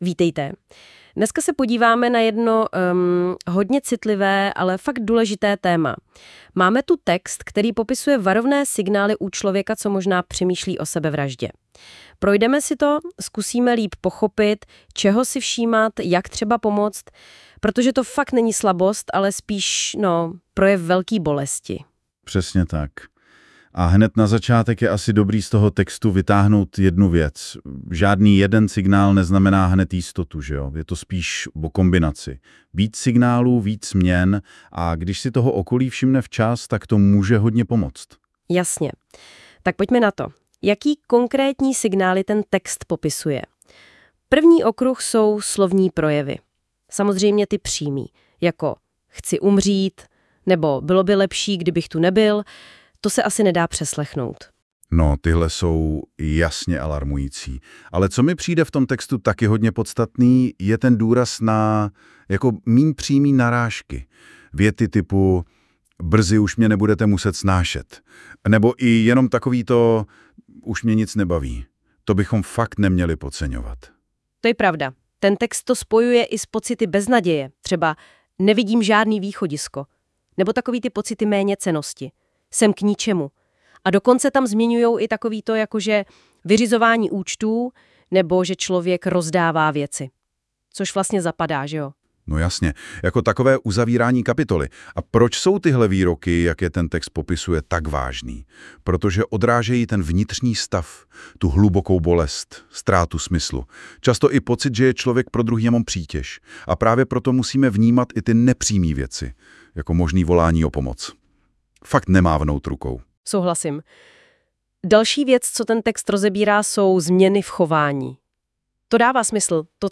Audioverze vytvořená na základě tohoto článku pomocí nástroje NotebookLM.